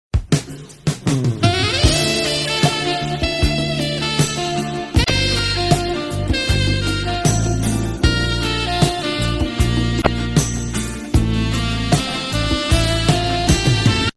ROMANTIC MUSIC - Meme Sounds
Play and share the "ROMANTIC MUSIC" meme sound effect